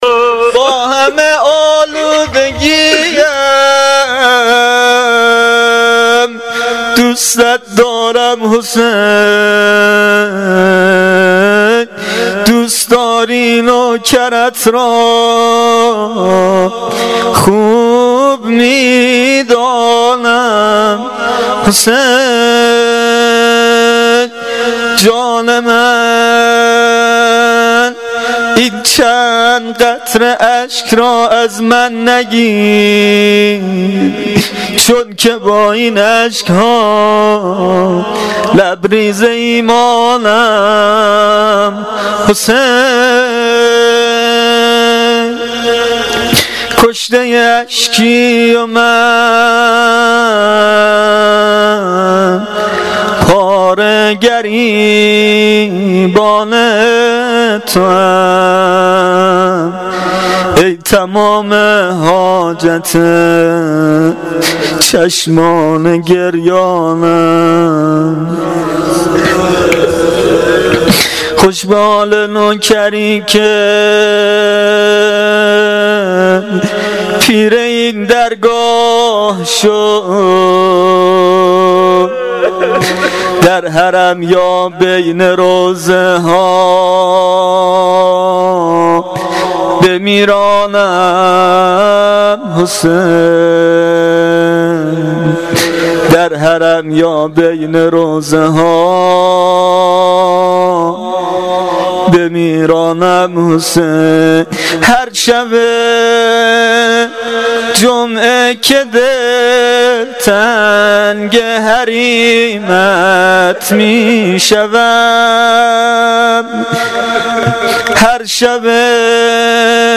مناجات و روضه شب دهم محرم الحرام 1396 (شب عاشورا)